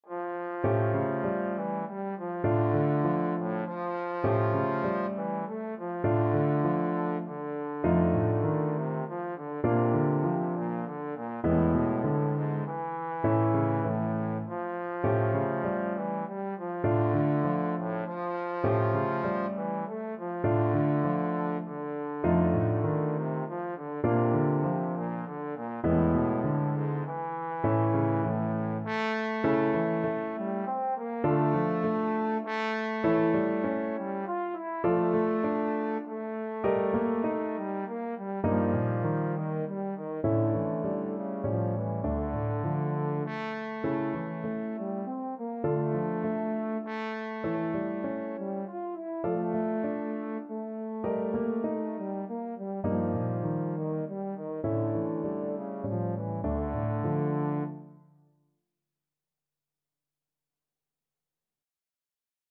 Free Sheet music for Trombone
Trombone
3/4 (View more 3/4 Music)
D minor (Sounding Pitch) (View more D minor Music for Trombone )
Etwas bewegt
Classical (View more Classical Trombone Music)